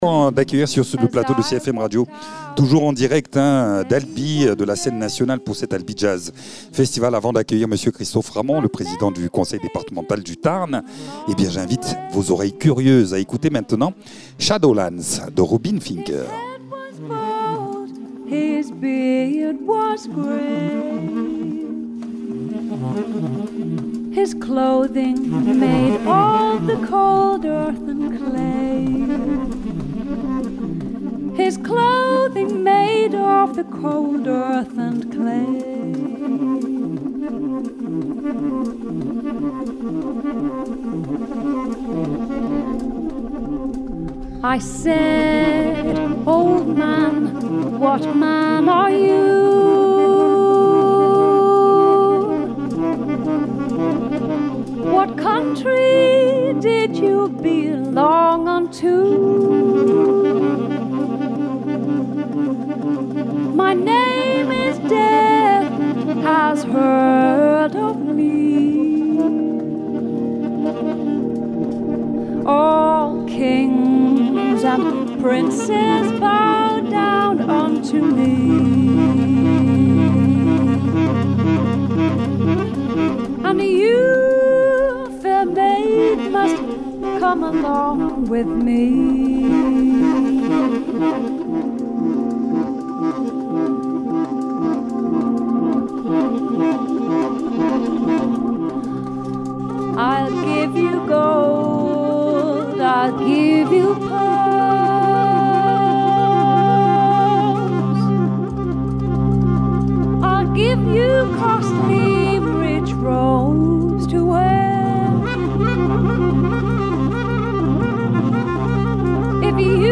Invité(s) : Christophe Ramond, président du département du Tarn